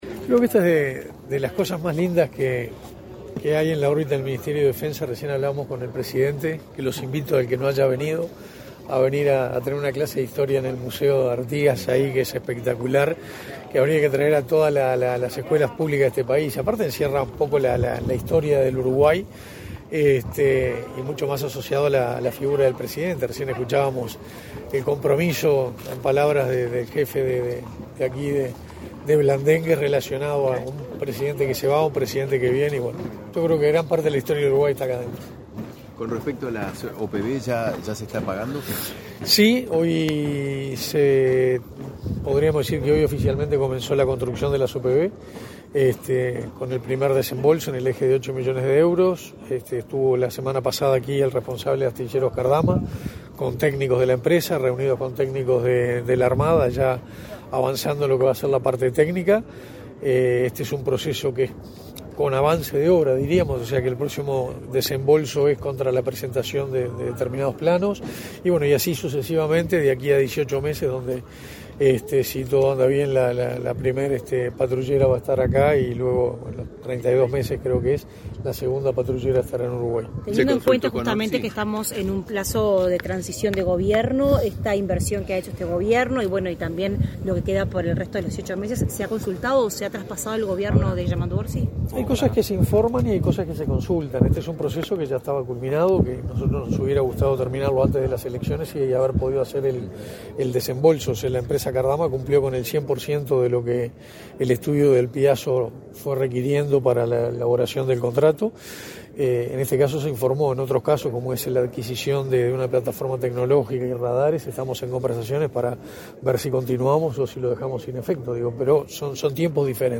Declaraciones a la prensa del ministro de Defensa Nacional, Armando Castaingdebat
El presidente de la República, Luis Lacalle Pou, participó, este 9 de diciembre, de la ceremonia por el 228.° aniversario del Regimiento Blandengues de Artigas de Caballería n.°1, cuerpo que integró el prócer, José Gervasio Artigas, desde 1797. Tras el evento, el ministro de Defensa Nacional, Armando Castaingdebat, realizó declaraciones a la prensa.